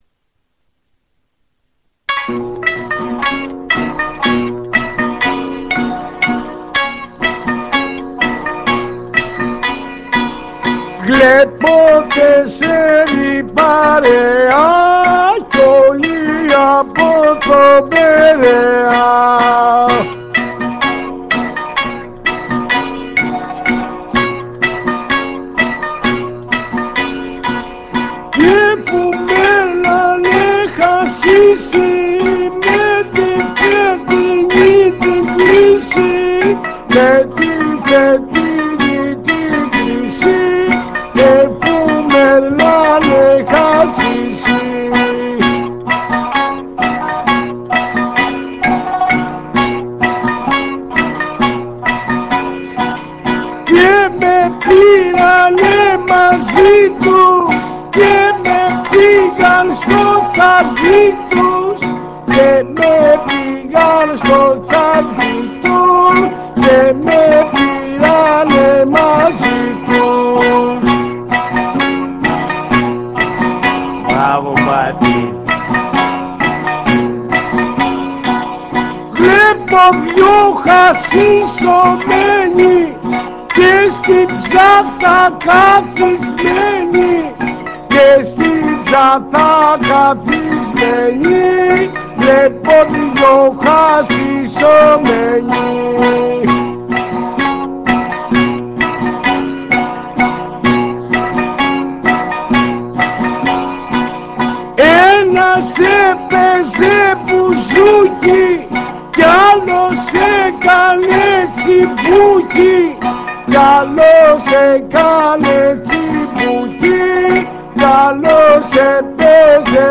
Forbidden rebetiko